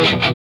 Index of /90_sSampleCDs/Roland L-CDX-01/GTR_GTR FX/GTR_Gtr Hits 1
GTR CHUCK01R.wav